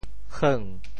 How to say the words 狠 in Teochew？
heung2.mp3